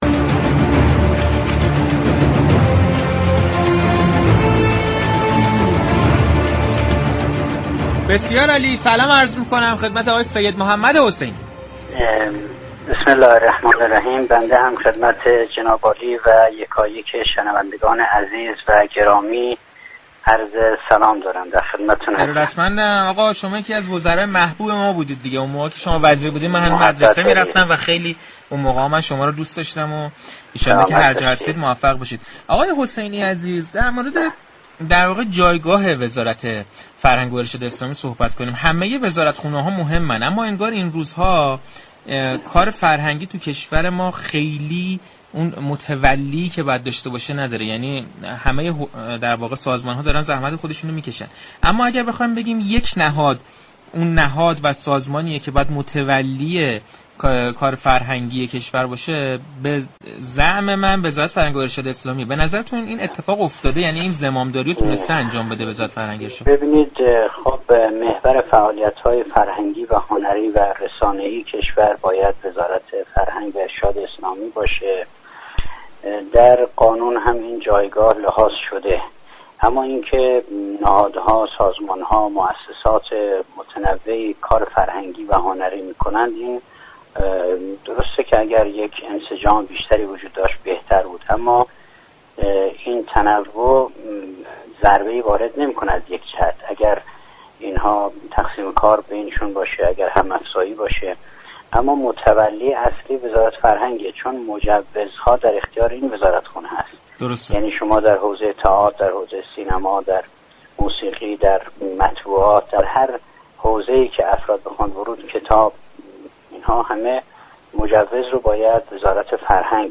سید محمد حسینی، وزیر فرهنگ و ارشاد اسلامی دوره‌ی دهم ریاست جمهوری در ارتباط با جایگاه فرهنگ در جامعه و نقش وزارت فرهنگ و ارشاد اسلامی در حمایت از فعالیت‌های فرهنگی با پشت صحنه‌ی رادیو تهران صحبت كرده و از لزوم توجه دولتمردان به فرهنگ گقت.